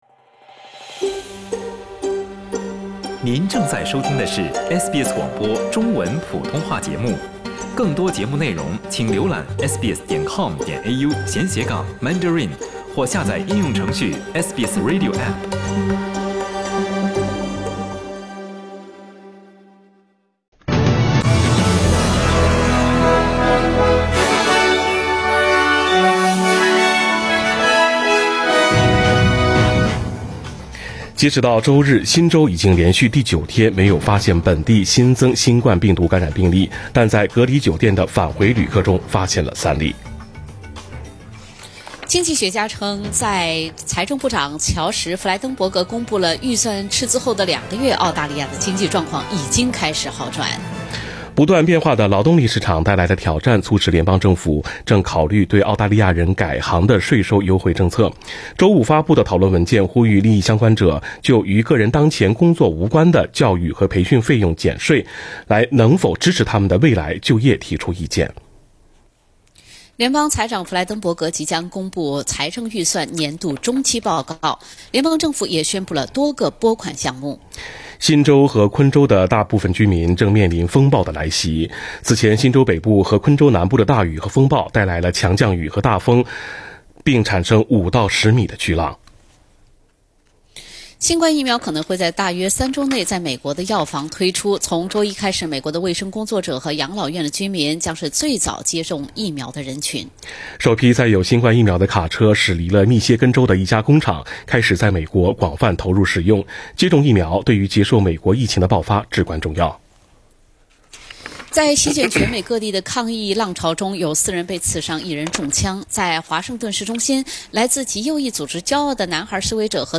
SBS早新聞 (12月14日）